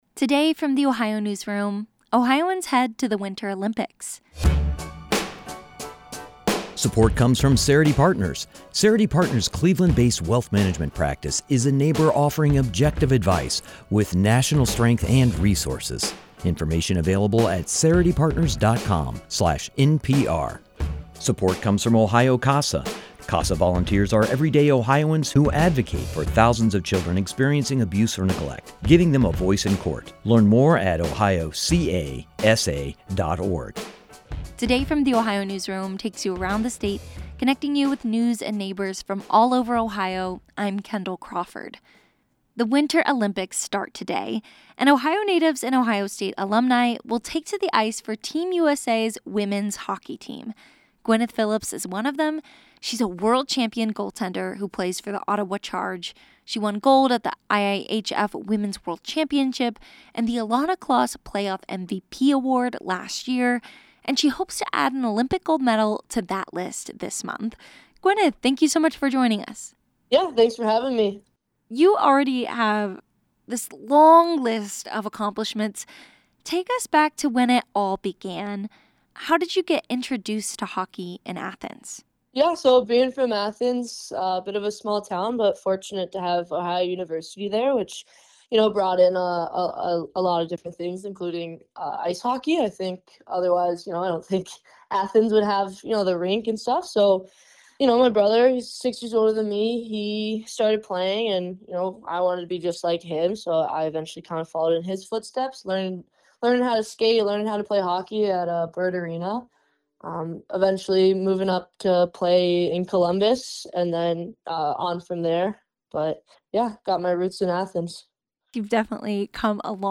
This interview has been lightly edited for brevity and clarity.